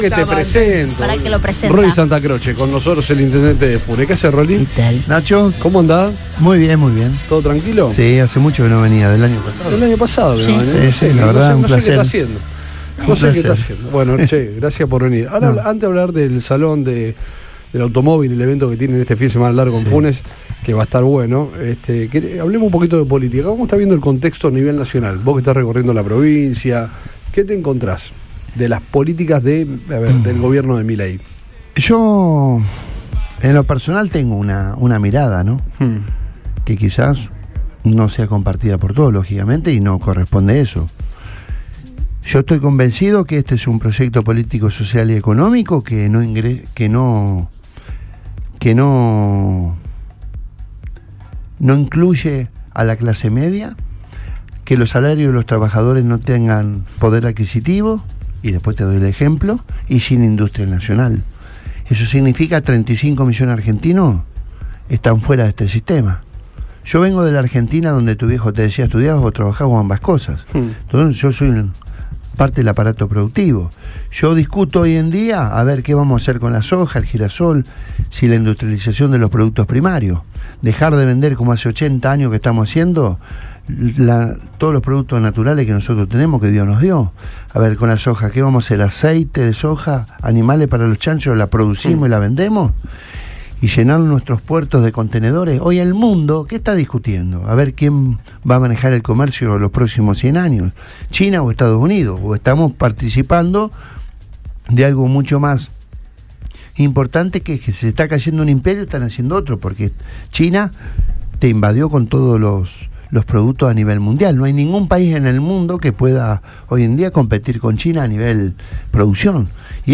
El intendente de Funes visitó los estudios de Radio Boing y charló con el equipo de Todo Pasa.